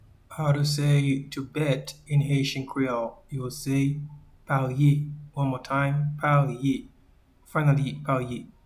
Pronunciation:
to-Bet-in-Haitian-Creole-Parye-1.mp3